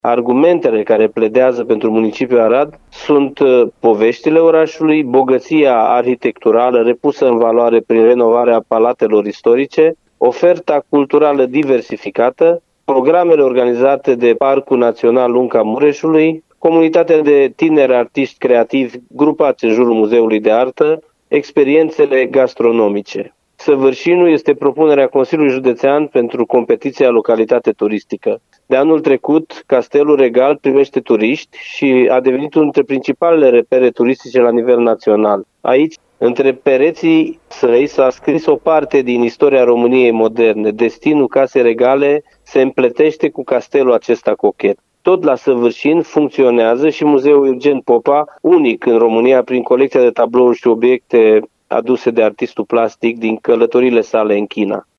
Președintele Consiliului Județean Arad, Iustin Cionca spune care sunt atuurile celor două localități.